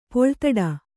♪ poḷtaḍa